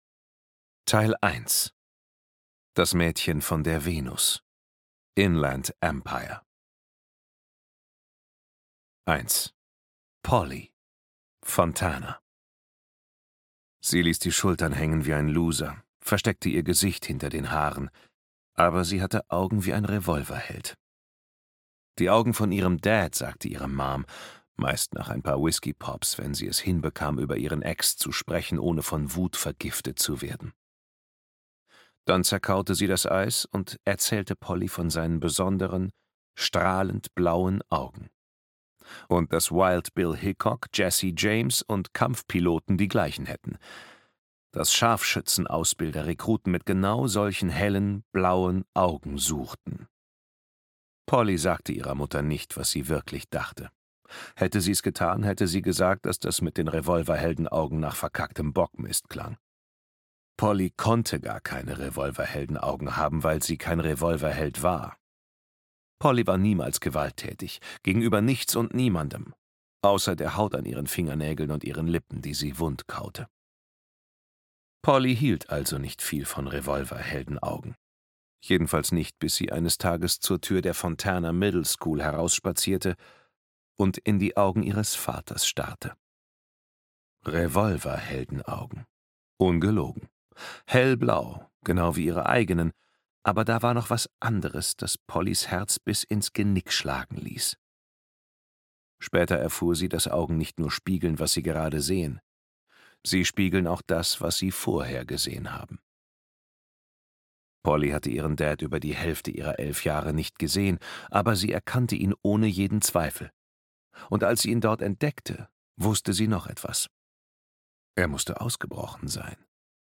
Die Rache der Polly McClusky - Jordan Harper - Hörbuch